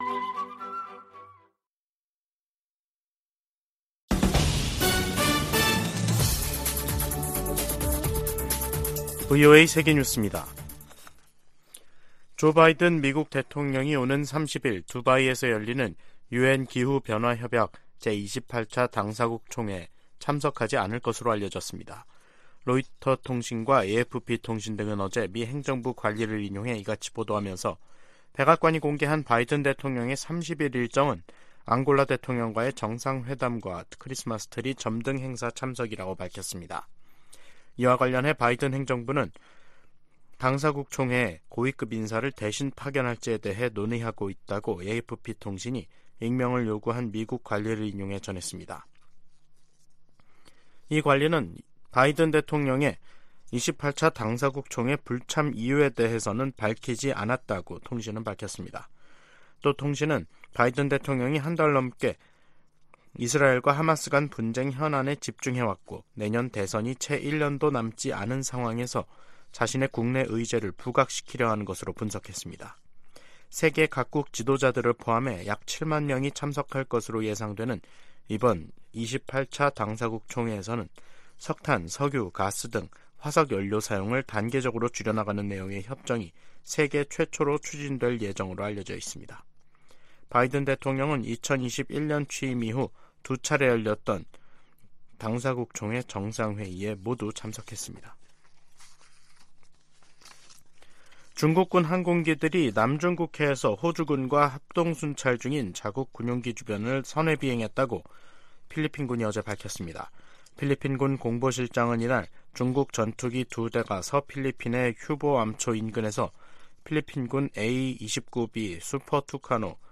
VOA 한국어 간판 뉴스 프로그램 '뉴스 투데이', 2023년 11월 27일 2부 방송입니다. 한국 군 당국은 북한 군이 9.19 남북 군사합의에 따라 파괴한 비무장지대 내 감시초소에 병력과 장비를 다시 투입하고 감시소를 설치 중인 것으로 드러났다고 밝혔습니다. 유엔 안전보장이사회가 북한 위성 발사 대응 긴급회의를 엽니다. 북한과 러시아가 군사적 밀착을 가속화하는 가운데 다른 분야로 협력을 확대하고 있다고 전문가들은 평가하고 있습니다.